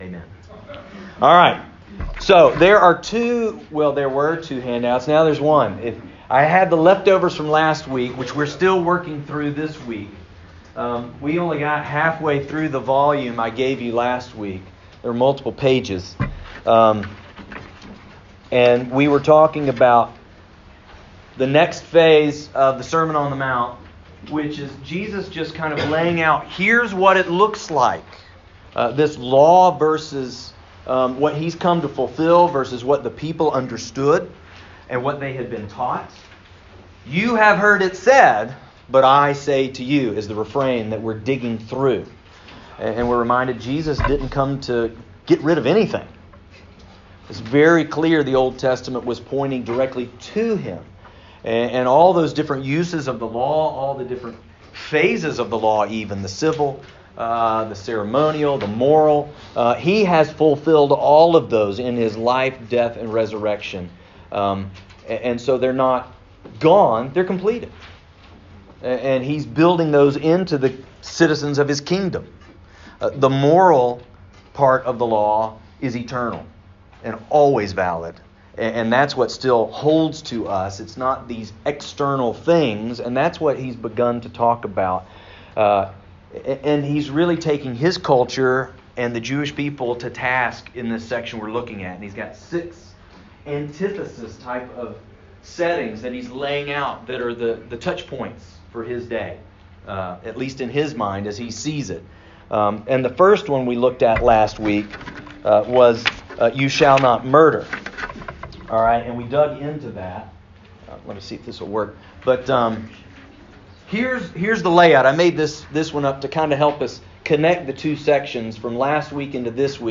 Sermon on the Mount Service Type: Sunday School %todo_render% « A Life Vision